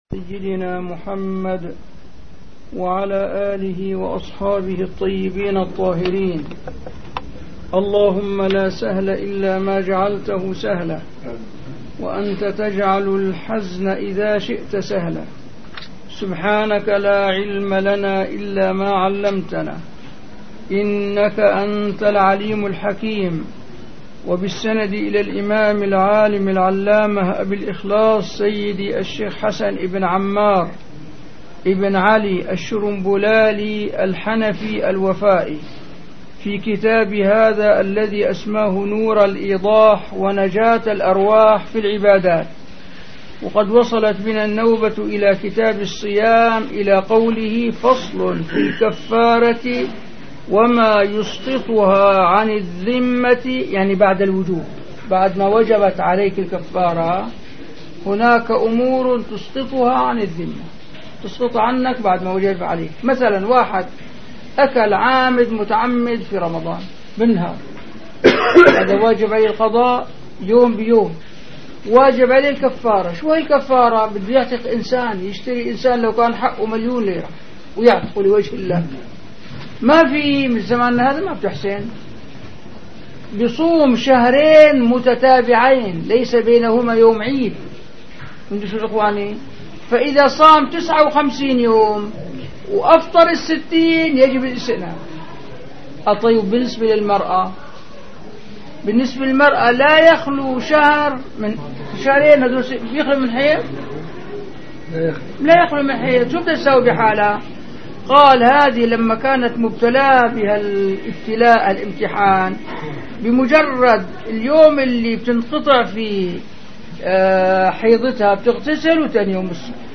- الدروس العلمية - الفقه الحنفي - مراقي الفلاح - الدرس الثالث والستون